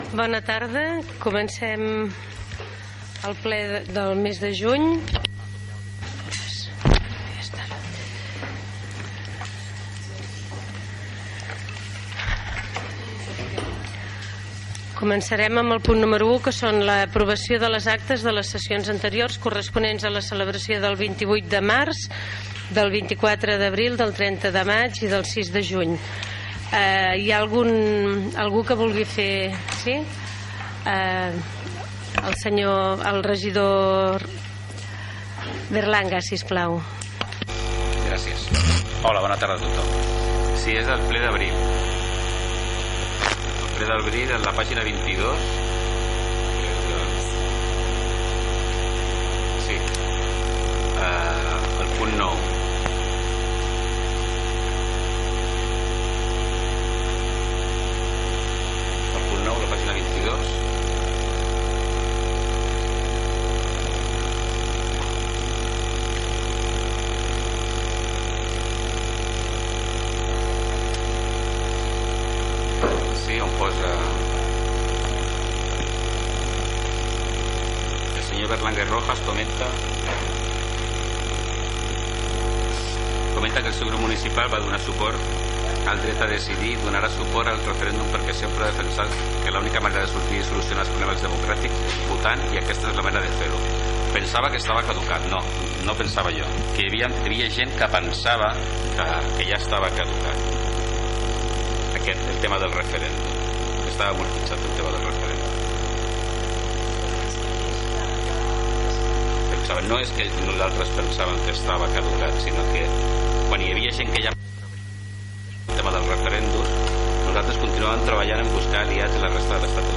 Transmissió en directe del ple municipal de l'Ajuntament de Sant Sadurní d'Anoia presidit per l'alcaldessa Maria Rosell . Aprovació de les actes
Informatiu